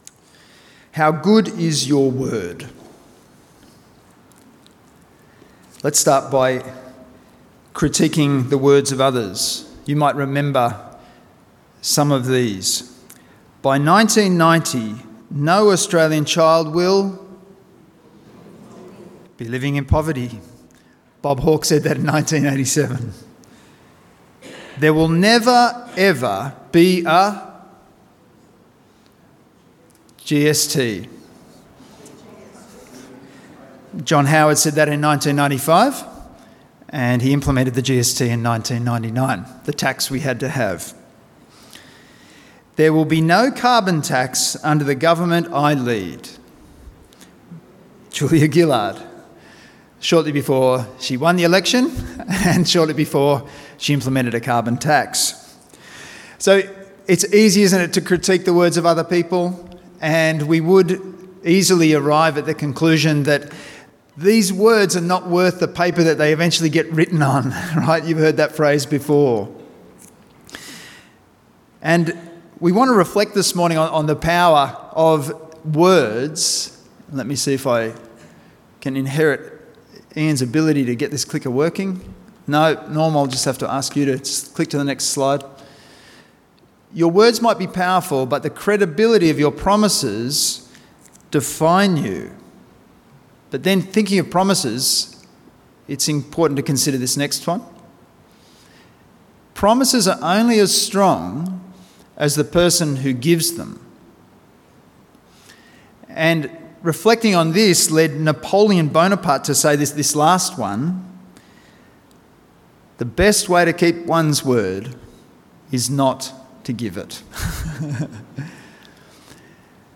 A message from the series "Meet Jesus in Isaiah."